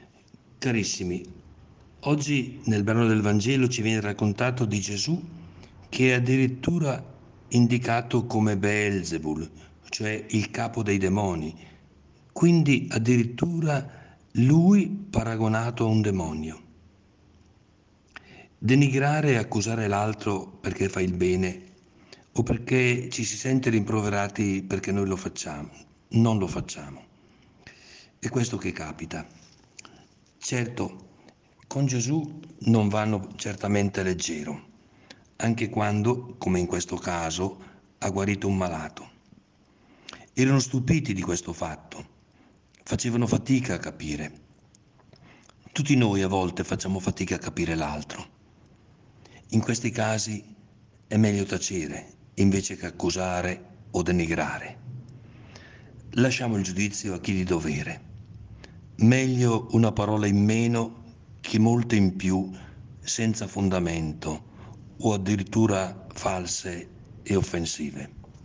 PODCAST – Prosegue la rubrica podcast “In ascolto della Parola”, curata da Mons. Carlo Bresciani, vescovo della Diocesi di San Benedetto del Tronto – Ripatransone – Montalto, il quale ci accompagnerà con un contributo quotidiano per tutta la Quaresima.
In questi 40 giorni il Vescovo commenterà la Parola di Dio per trarne ispirazione per la giornata.